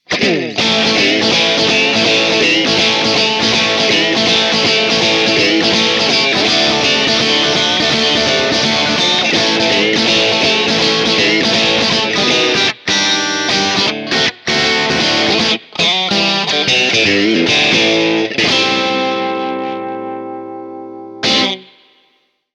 Tutte le clip audio sono state registrate con testata a valvole artigianale e cassa 2×12 equipaggiata con altoparlanti Celestion Creamback 75 impostato su un suono estremamente clean.
Chitarra: Fender Stratocaster (pickup al ponte + centrale e volume della chitarra a 7)
Genere: Rock/Blues
Boost: 8/10
Twang: 9/10
Blackface Output: 4/5
Rock-Strat.mp3